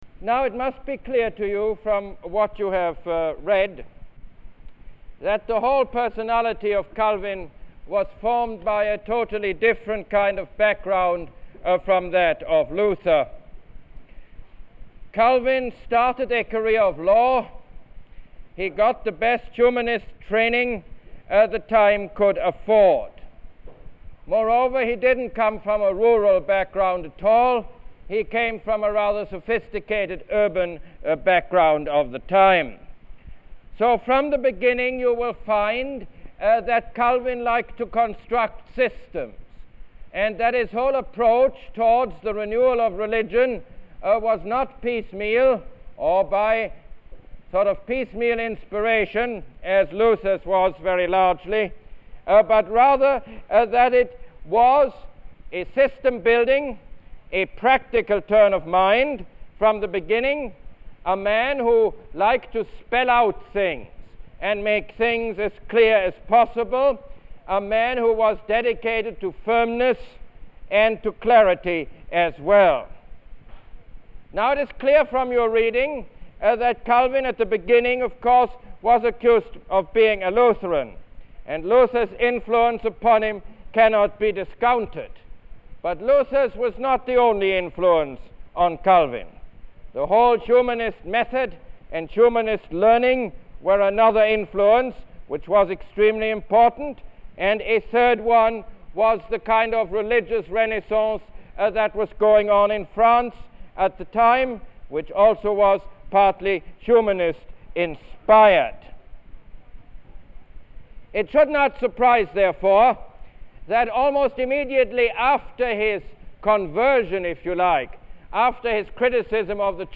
Lecture #6 - John Calvin